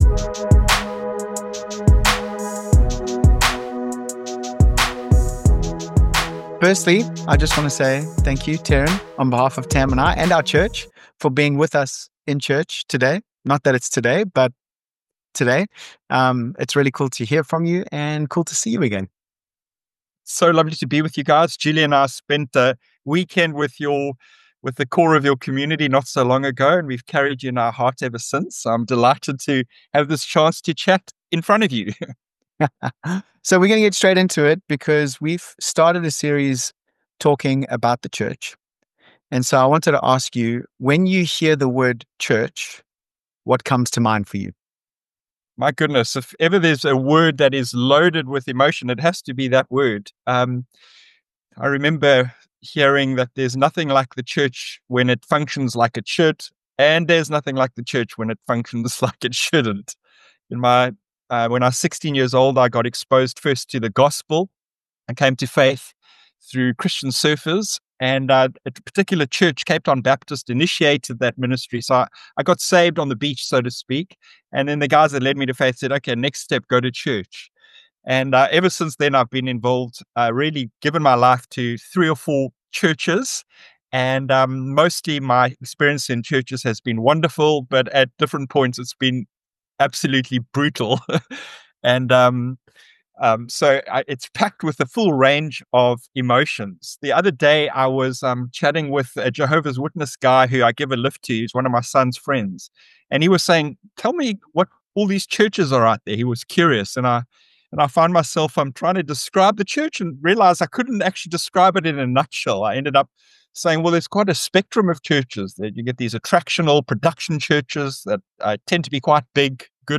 We are currently in a teaching series focussing on the church.